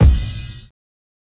Kicks
KICK_JUDY.wav